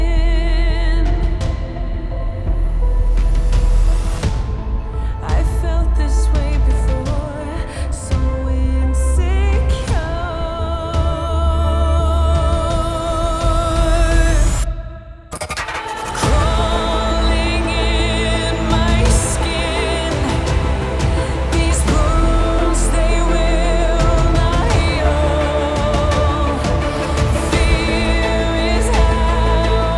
Soundtrack Pop Adult Contemporary
Жанр: Поп музыка / Соундтрэки